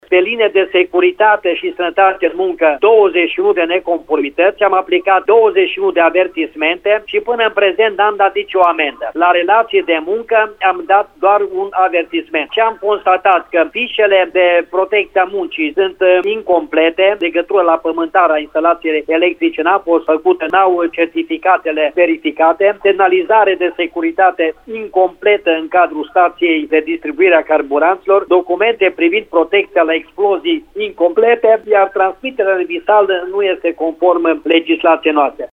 În cele 19 stații controlate, au fost descoperite mai multe neconformități, spune șeful ITM Timiș, Pavel Kașai.